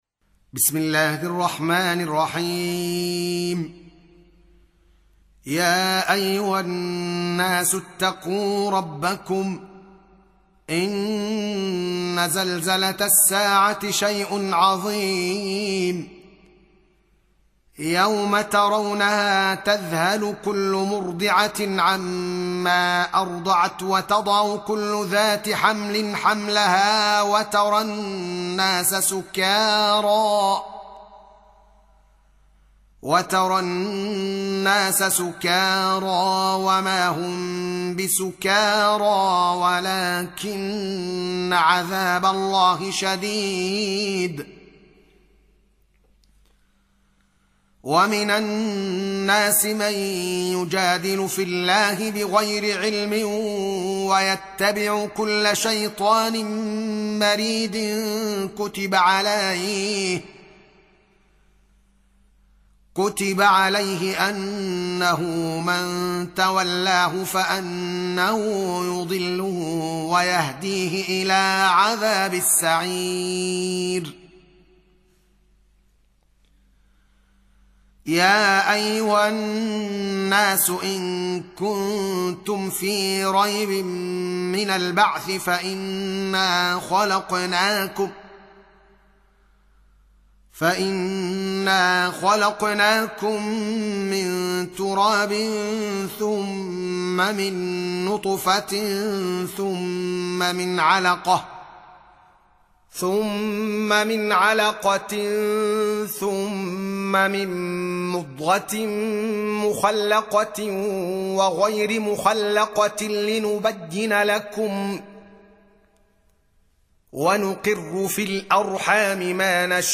Surah Repeating تكرار السورة Download Surah حمّل السورة Reciting Murattalah Audio for 22. Surah Al-Hajj سورة الحج N.B *Surah Includes Al-Basmalah Reciters Sequents تتابع التلاوات Reciters Repeats تكرار التلاوات